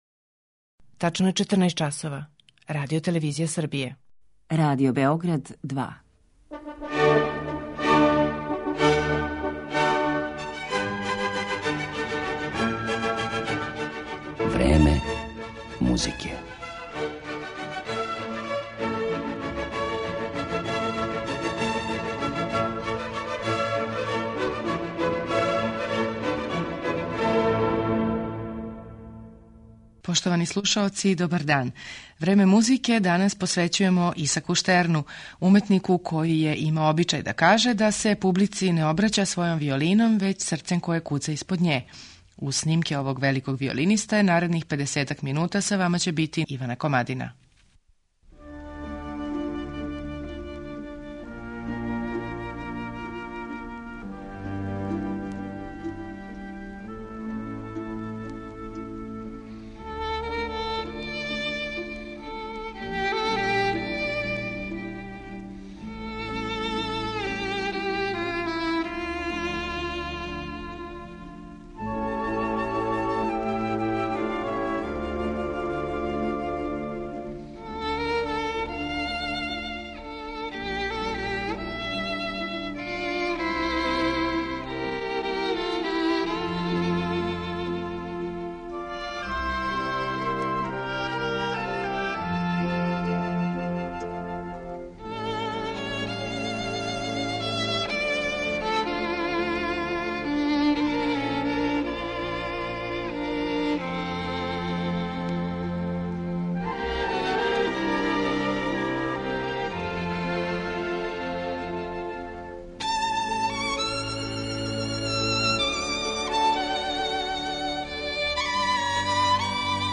Представићемо великог виолинисту Исака Штерна
са виолином у руци